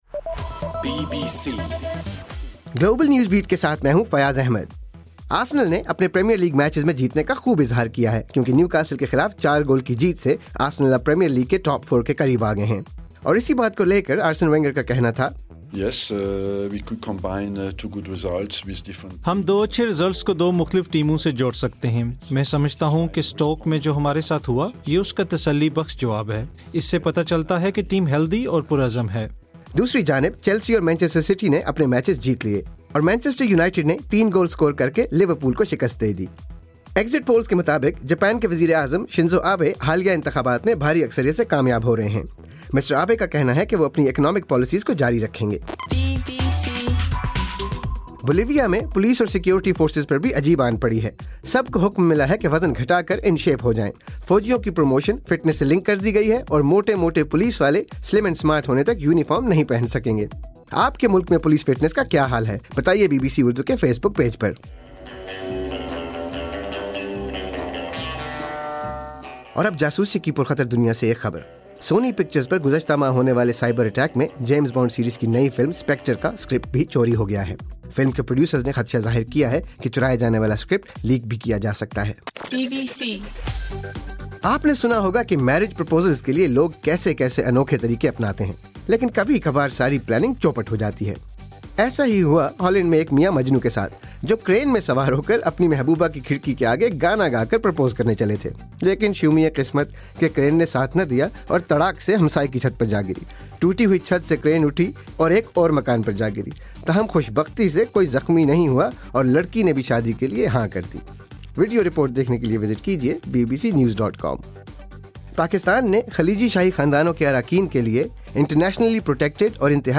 دسمبر 14: رات 11 بجے کا گلوبل نیوز بیٹ بُلیٹن